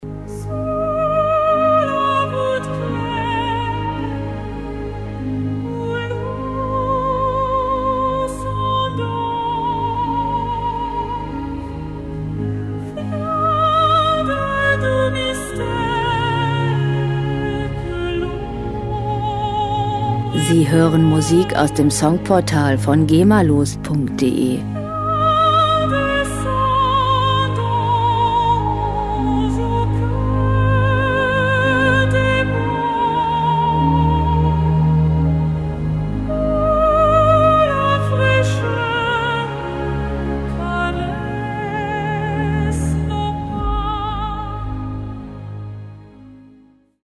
Filmmusik
Musikstil: Crossover
Tempo: 70 bpm
Tonart: As-Dur
Charakter: friedvoll, malerisch
Instrumentierung: französische Sopran Sängerin, Orchester